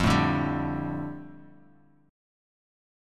F6add9 Chord
Listen to F6add9 strummed